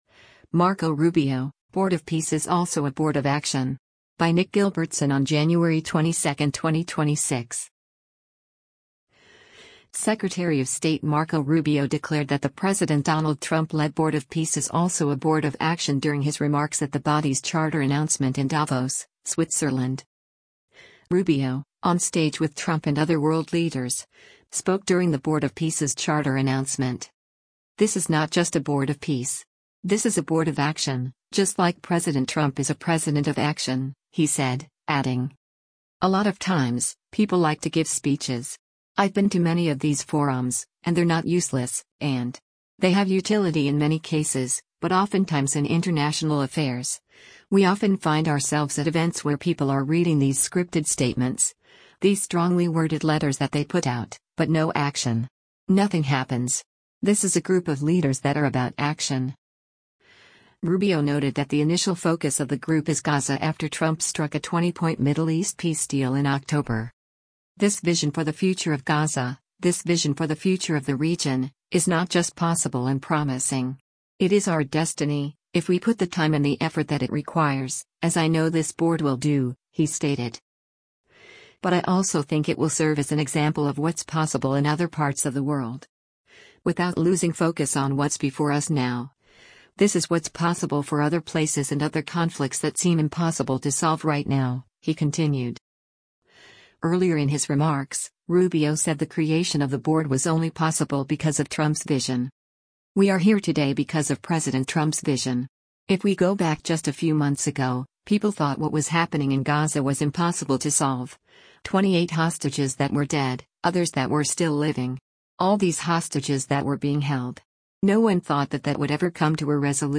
Secretary of State Marco Rubio declared that the President Donald Trump-led Board of Peace is also “a board of action” during his remarks at the body’s charter announcement in Davos, Switzerland.
Rubio, on stage with Trump and other world leaders, spoke during the Board of Peace’s charter announcement.